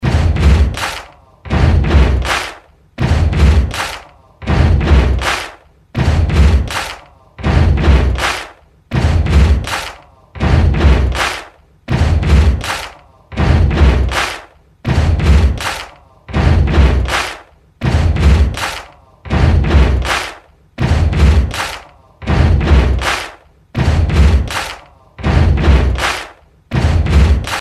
We Will Rock You Claps
Category: Sports   Right: Personal
Tags: Princeton Hockey Baker Rink